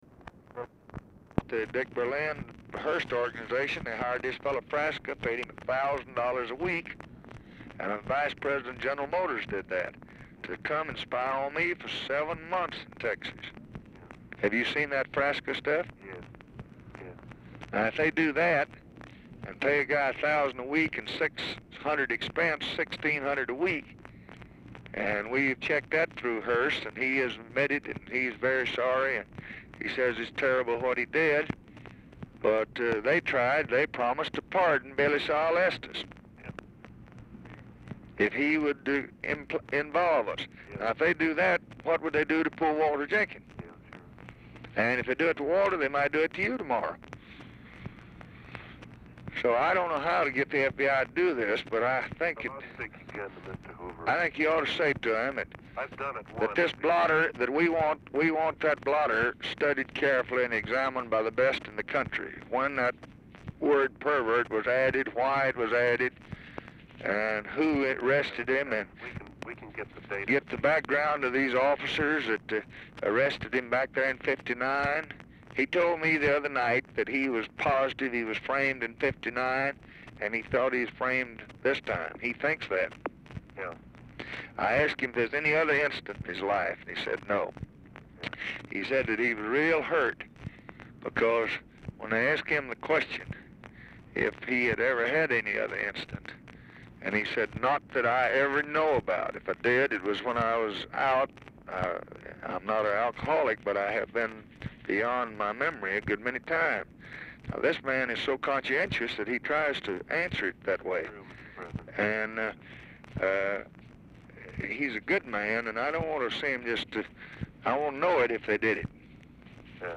Telephone conversation # 6326, sound recording, LBJ and NICHOLAS KATZENBACH, 11/11/1964, 7:38PM | Discover LBJ
Format Dictation belt
Location Of Speaker 1 LBJ Ranch, near Stonewall, Texas